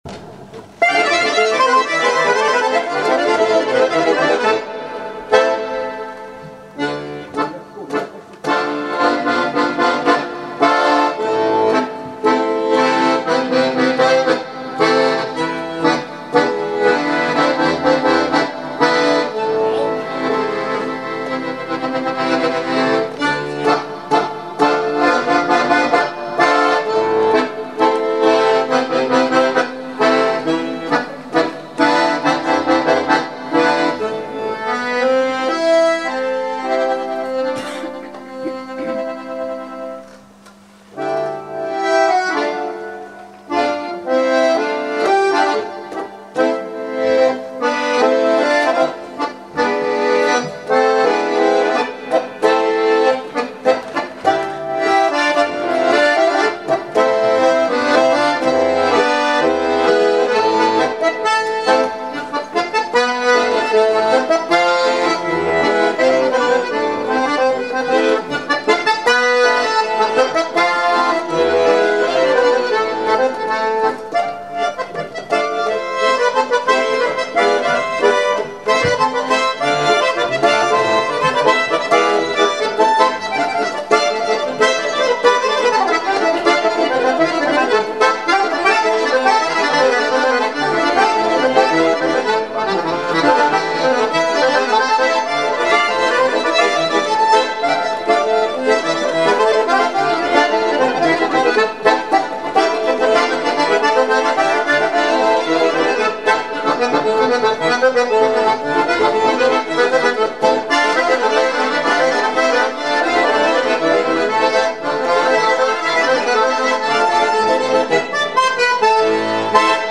гармонь (закрыта)